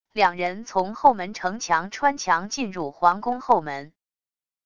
两人从后门城墙穿墙进入皇宫后门wav音频